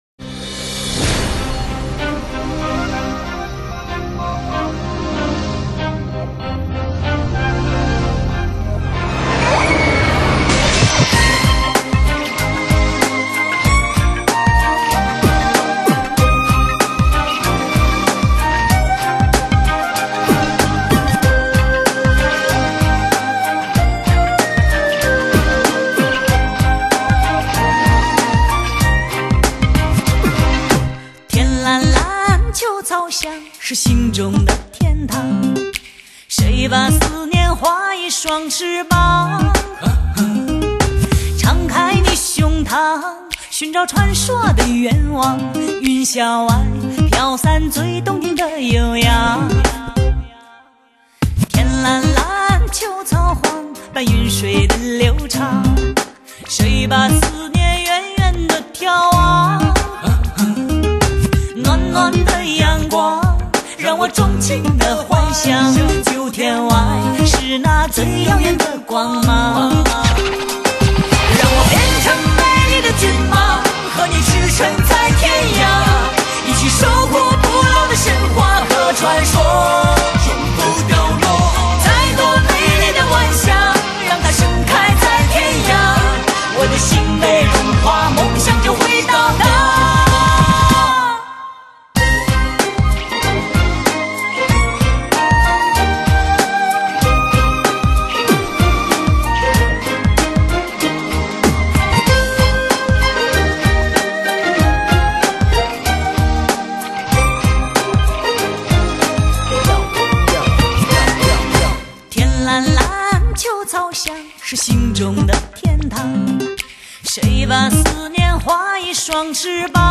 用最纯净的嗓音，描绘情归何处……